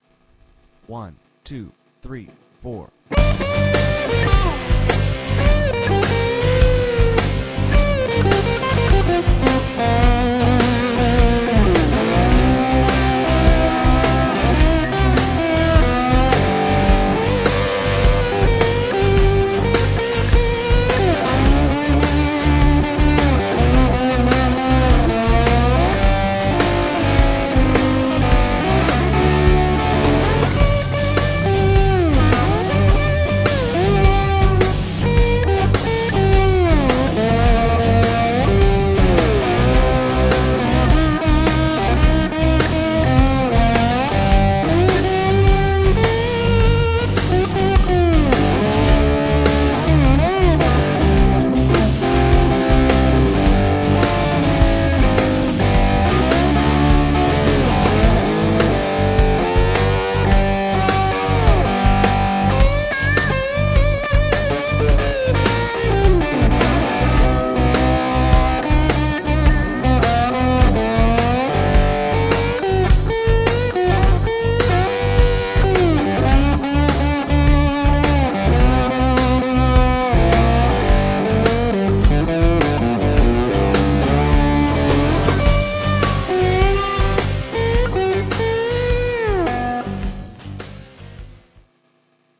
Tämä on rootsskaba roots-musiikista kiinnostuneille, joilla ei välttämättä ole taitoa tai kokemusta, jotta julkaisukynnys ylittyisi olemassa oleviin (roots, jazz, soolo, bassolinja) skaboihin.
-soita soolosi annetun taustan päälle
-taustan tulee olla sointukierroltaan blues (I-IV-V)- sointuja ja tyyliä soveltaen.